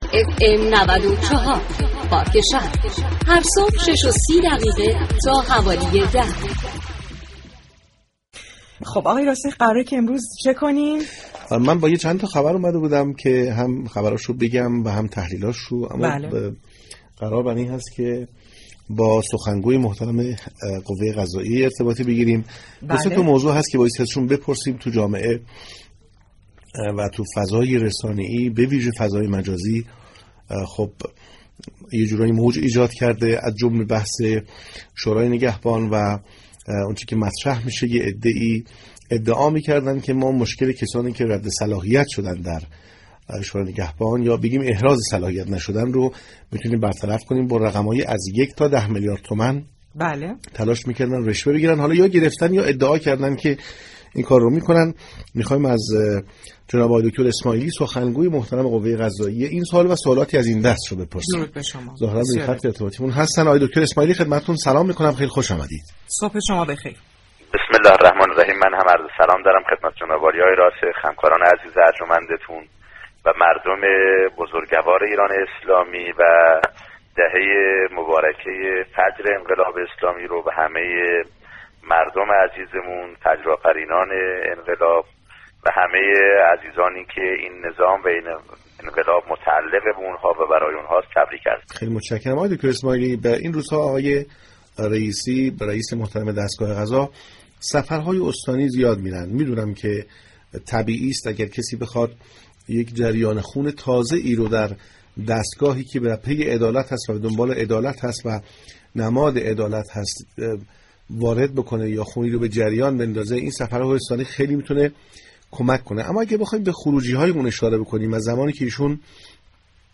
دریافت فایل غلامحسین اسماعیلی سخنگوی قوه قضاییه در گفت‌وگو با پارك شهر رادیو تهران درباره آخرین وضعیت پرونده «روح الله زم» گفت: ما اهل احتمالات نیستیم و صبر می‌كنیم كه رسیدگی‌ها انجام شود و قاضی تصمیم بگیرد.